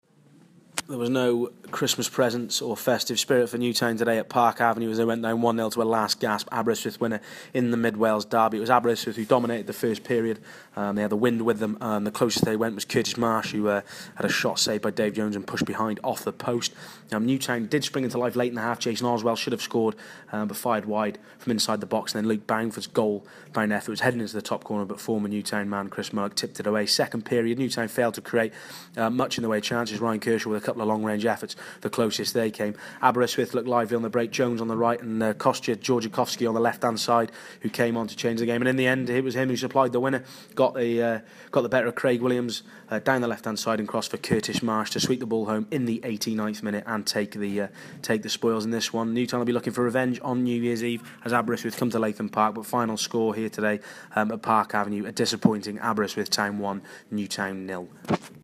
AUDIO REPORT- Aber 1-0 Robins